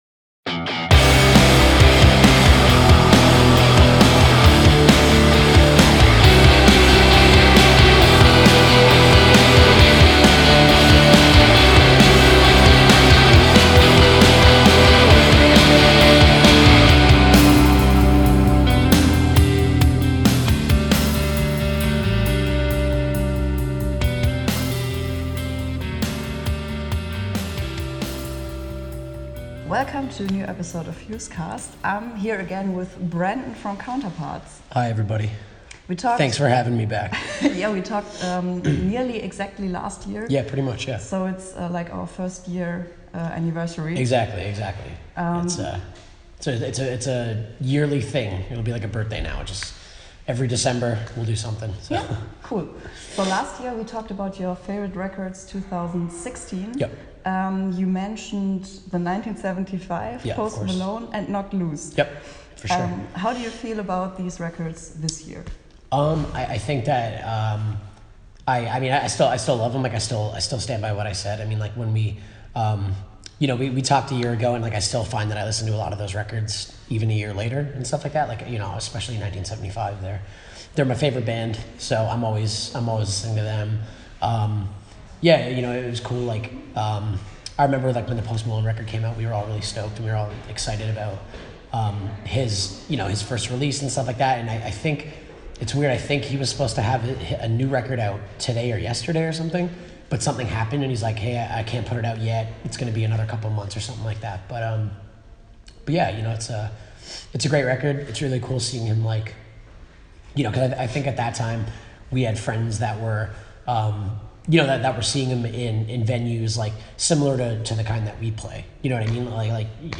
Ein kurzes Interview, bis der große Jahresrückblick von uns kommt.